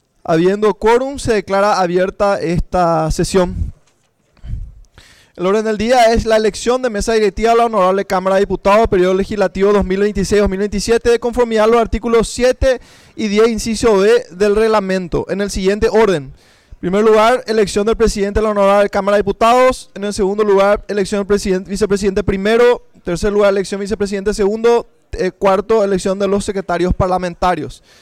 Sesión Preparatoria, 3 de marzo de 2026
Lectura de la resolución de convocatoria a Sesión Preparatoria.